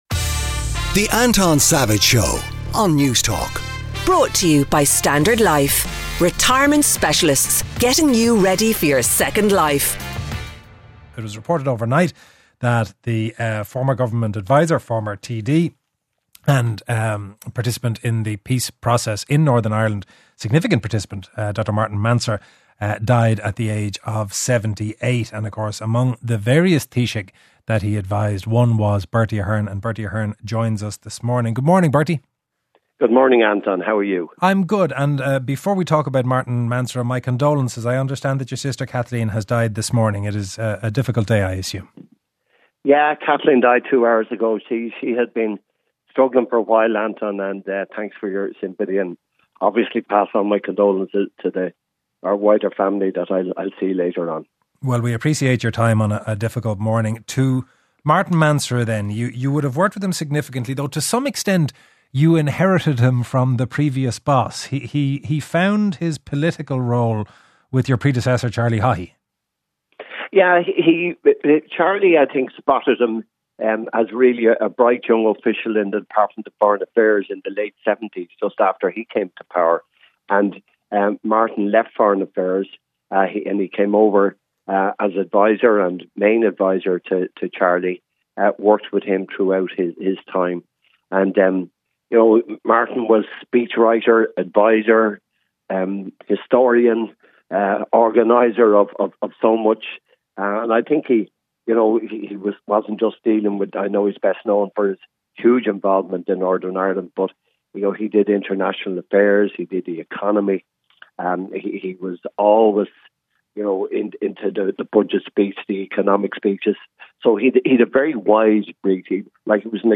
To reflect on his life and contribution, Anton is joined by former Taoiseach Bertie Ahern.